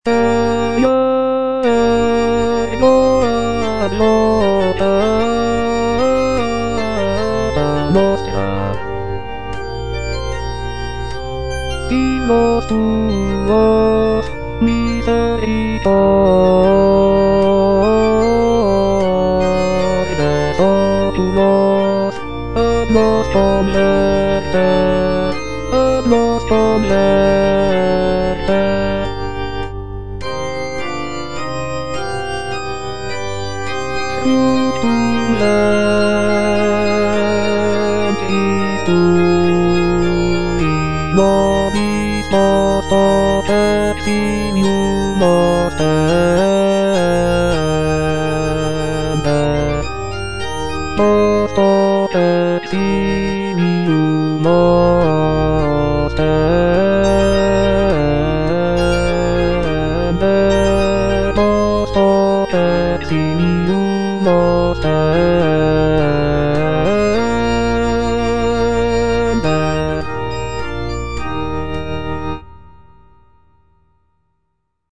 G.B. PERGOLESI - SALVE REGINA IN C MINOR Eja ergo advocata nostra - Bass (Voice with metronome) Ads stop: auto-stop
"Salve Regina in C minor" is a sacred choral work composed by Giovanni Battista Pergolesi in the early 18th century.